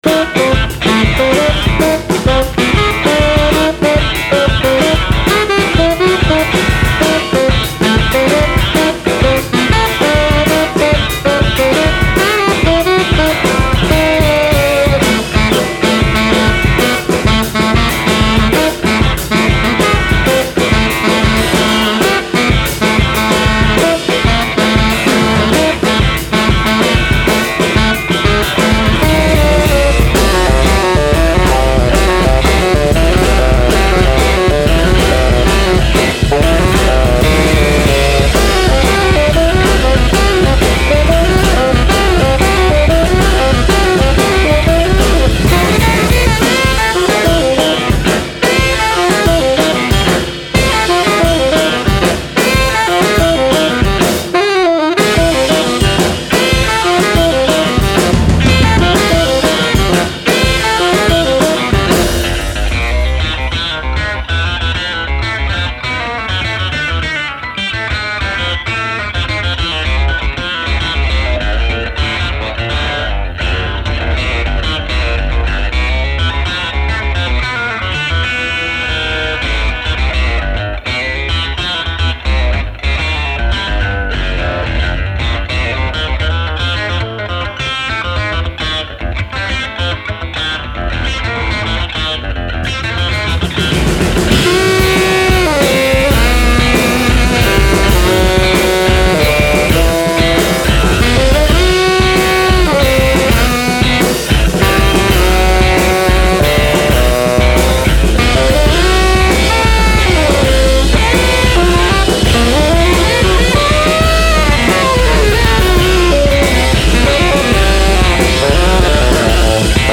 Guitar
Soprano & Tenor Saxophones
Drums